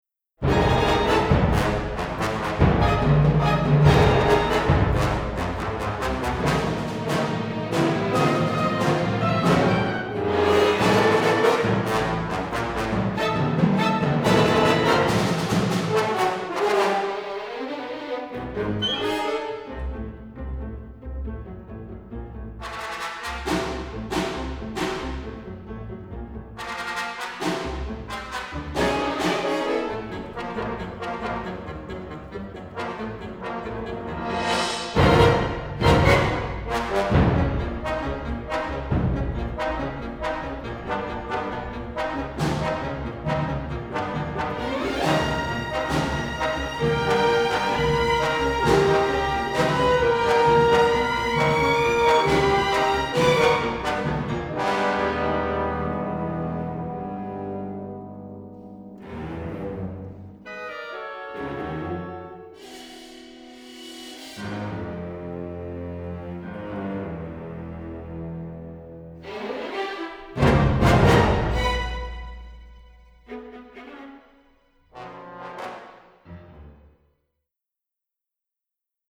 crisp recording